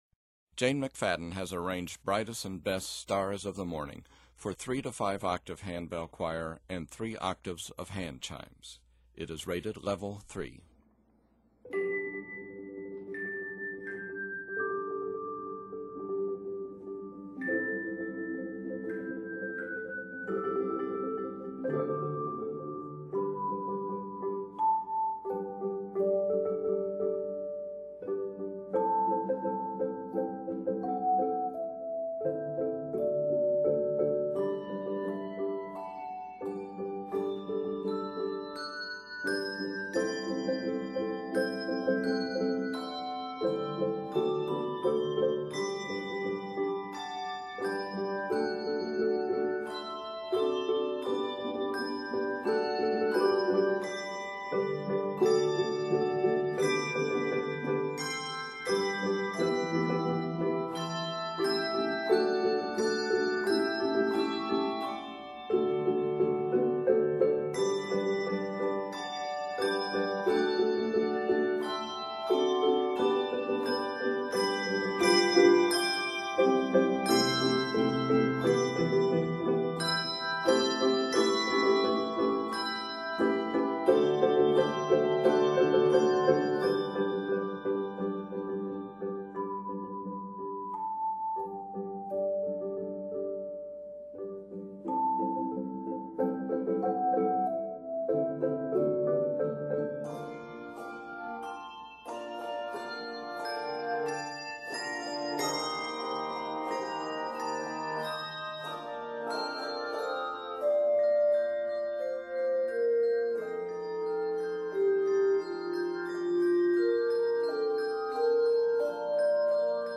set in G Major, Bb Major, and C Major